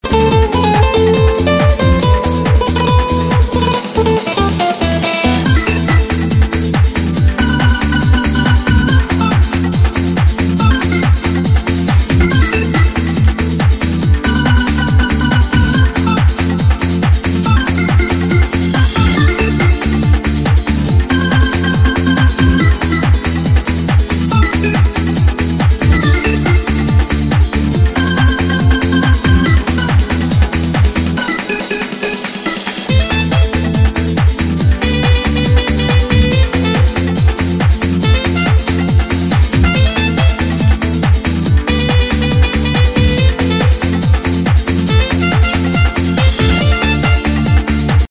• Качество: 96, Stereo
ритмичные
без слов
инструментальные
фолк
New Age